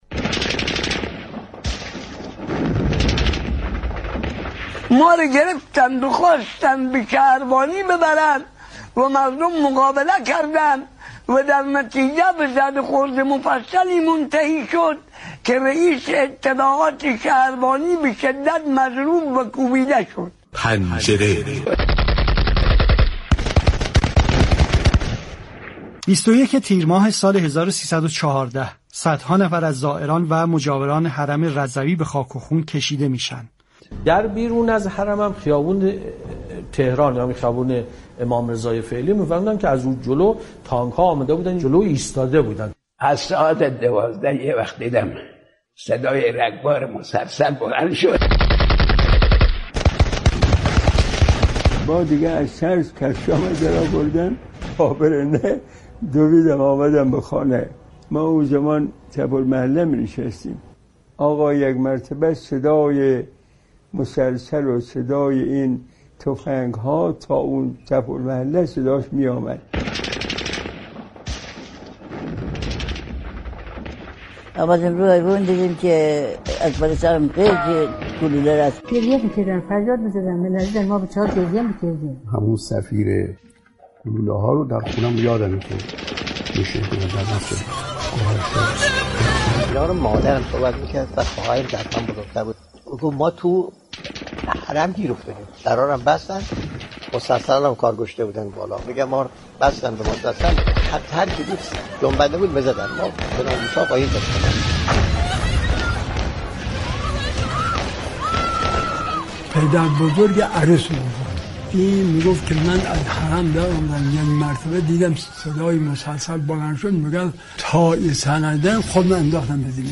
در مستند رادیویی «پناه‌بره»، صدای گریه كودكان، فریاد مادران و ضجه مجروحان با هم در هم می‌آمیزد؛ مستندی كه تاریخ را از زبان مردم عادی روایت می‌كند.
به گزارش روابط عمومی رادیو معارف، مستند رادیویی «پناه‌بره» به همت كارگروه مستند رادیو معارف با بهره‌گیری از خاطرات مردمی و صدای راویان، لحظه‌به‌لحظه وقایع خونین تیرماه 1314 در مسجد گوهرشاد را بازسازی می‌كند.